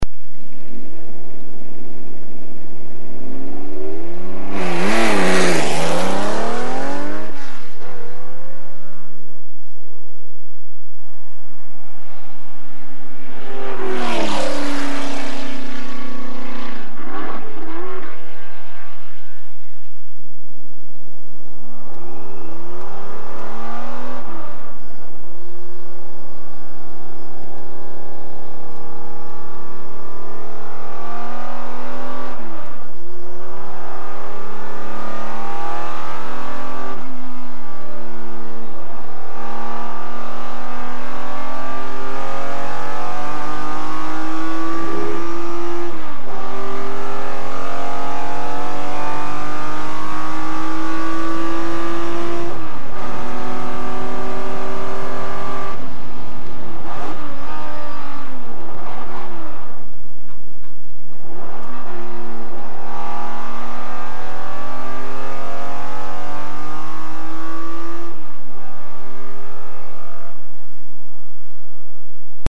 A jármű hangja általában hármas felosztásban hallható:
indulás, elhaladás, kocsiban ülve.
Ferrari_512_Bblm.mp3